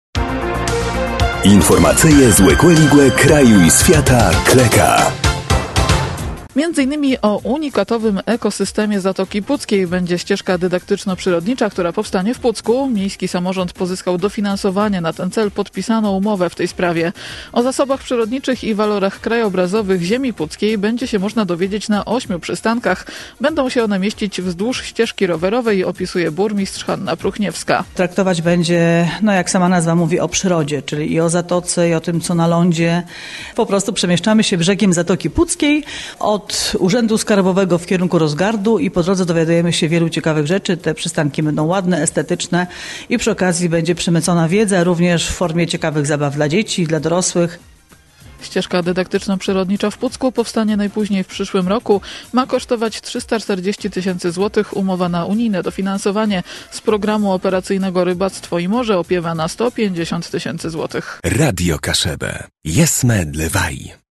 – Będą się one mieścić wzdłuż ścieżki rowerowej – opisuje burmistrz Hanna Pruchniewska.